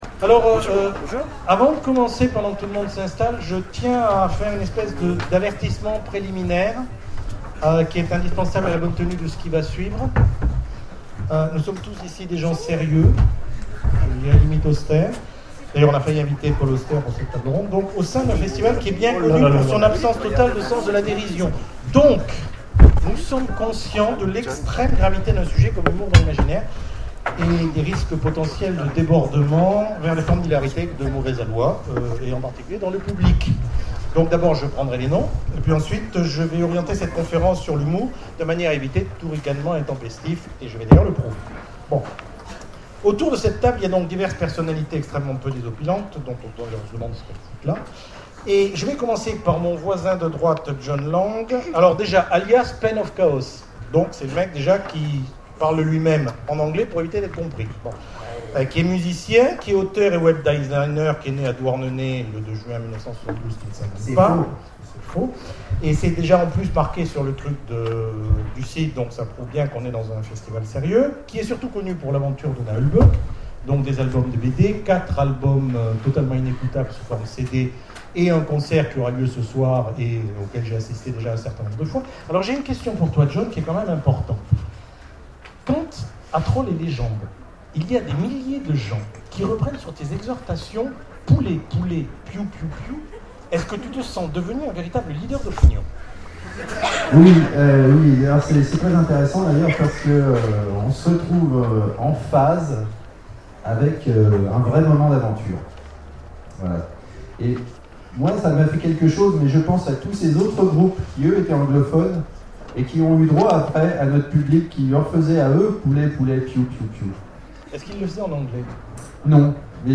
Imaginales 2012 : Conférence histoires d'en rire, l'humour dans l'Imaginaire